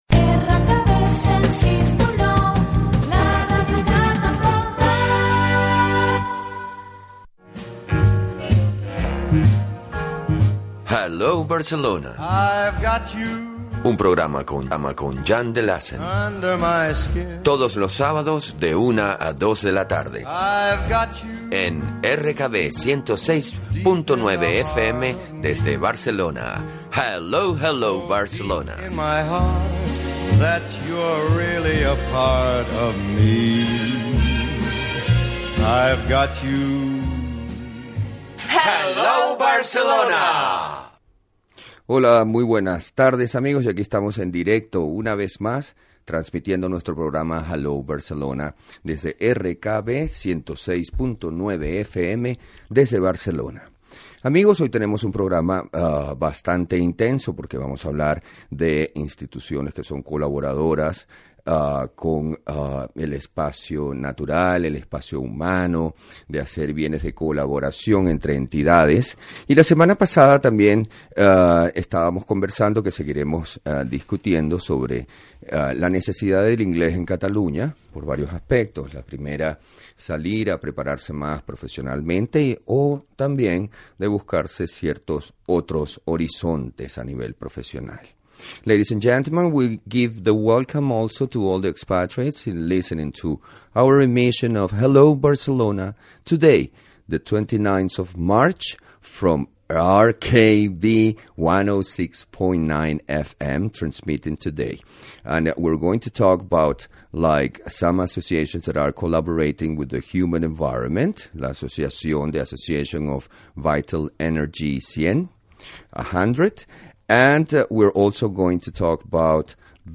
Indicatiu de la ràdio, careta del programa, sumari de continguts, presentació en anglès, publicitat, salutació a l'invitat
Entreteniment
FM